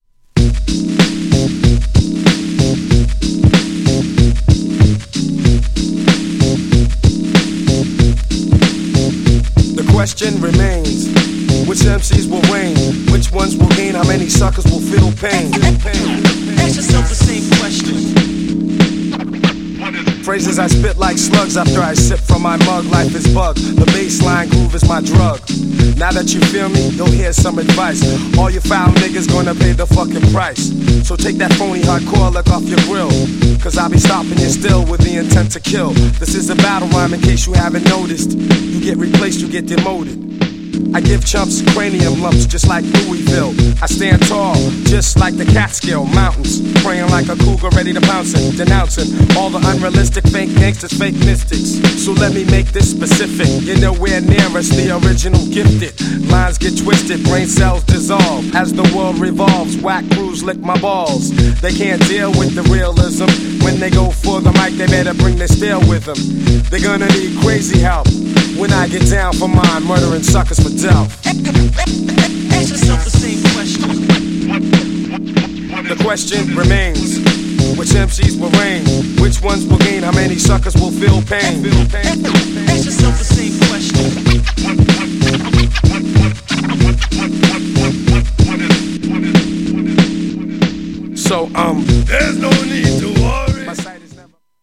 GENRE Hip Hop
BPM 81〜85BPM